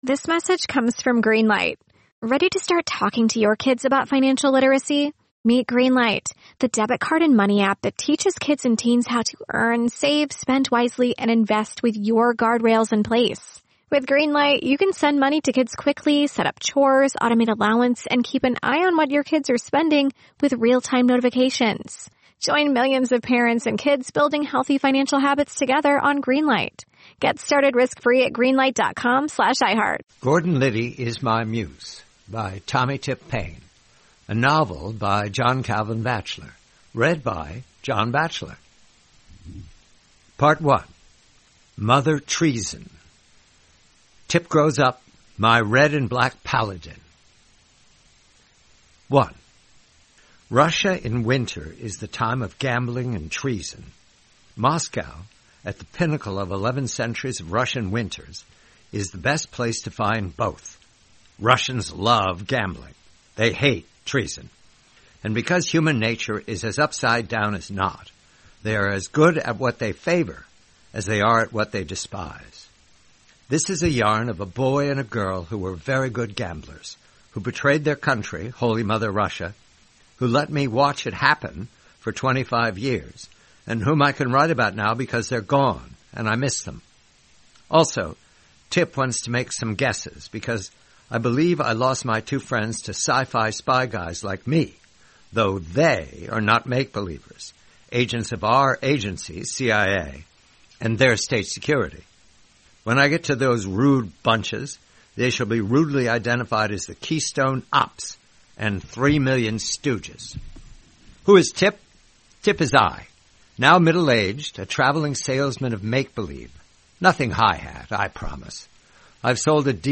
1/7: "Mother Treason," a story from the collection, "Gordon Liddy Is My Muse," by John Calvin Batchelor. Read by John Batchelor.